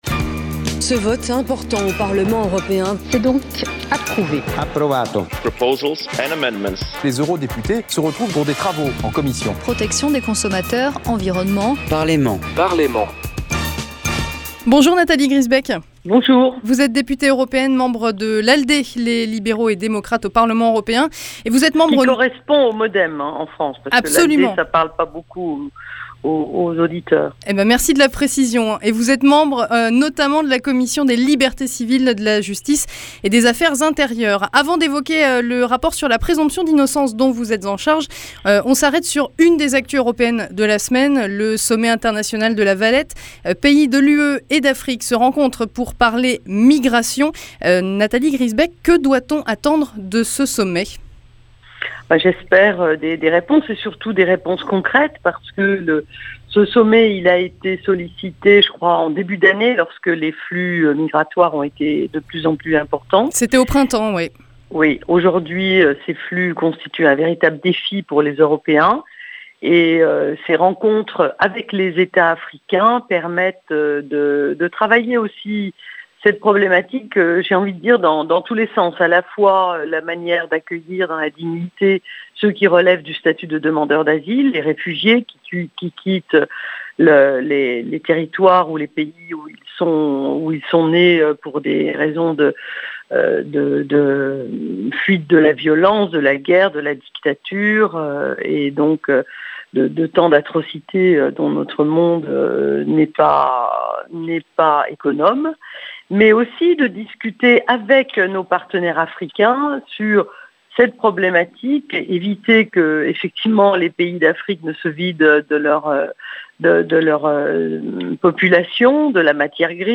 Interview sur les migrations et sur la présomption d'innocence
Nathalie Griesbeck était l’invitée de l’émission « Parlez m’en » sur Euradionantes.